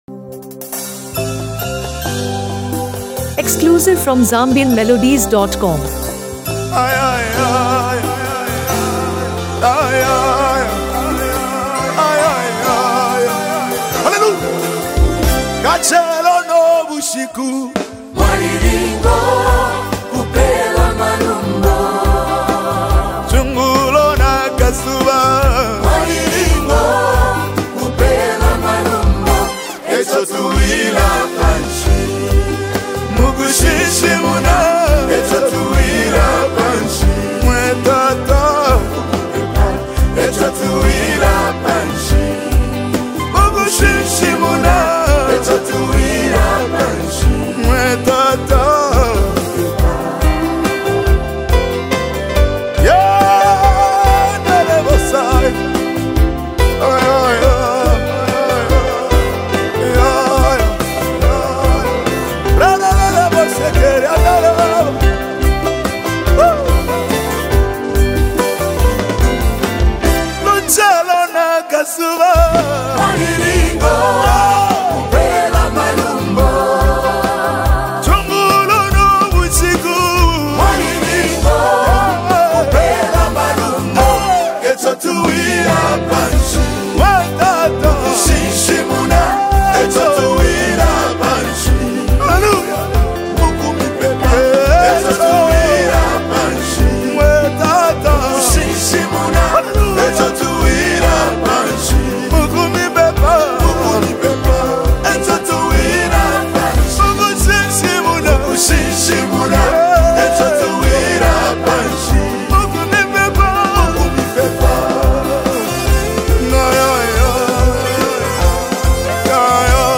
A Spirit-Lifting Gospel Anthem
soul-stirring gospel track